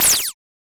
Roland.Juno.D _ Limited Edition _ GM2 SFX Kit _ 11.wav